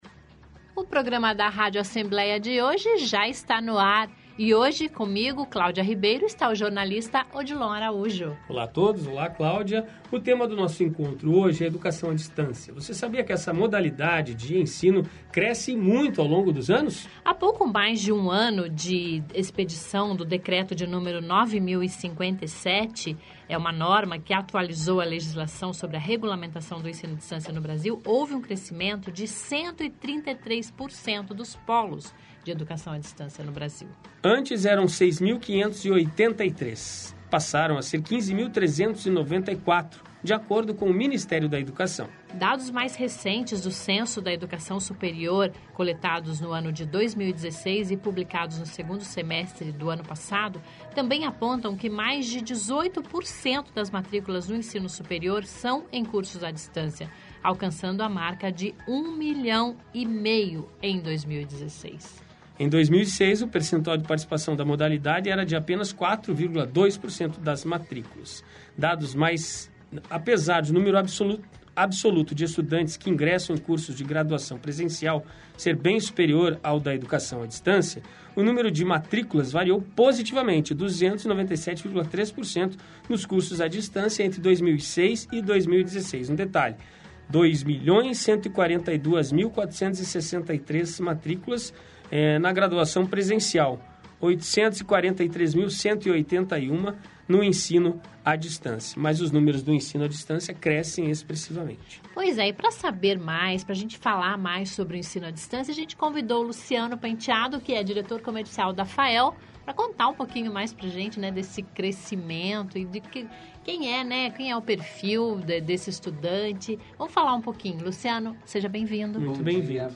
O crescimento da educação à distância é o assunto da nossa entrevista de hoje